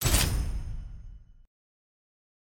sfx-uikit-arena-modal-click.ogg